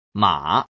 The 3rd tone “ˇ” like in mǎ Low then rising (